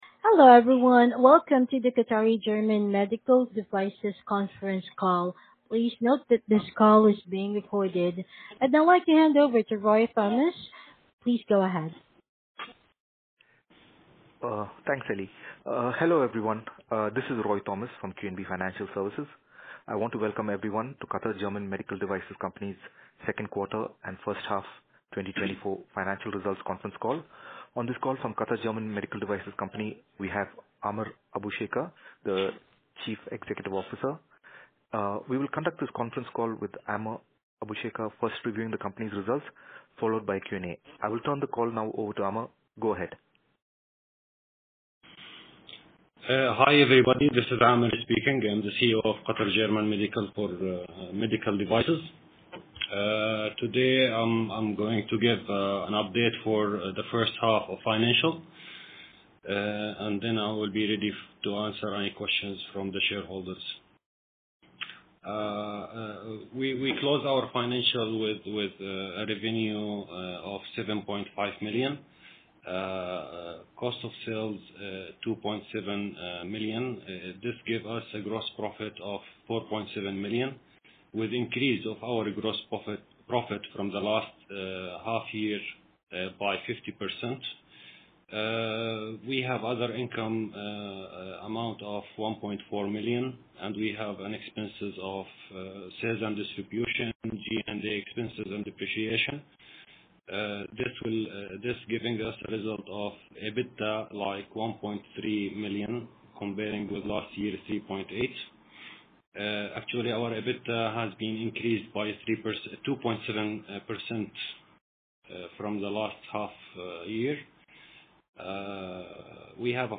Conference Call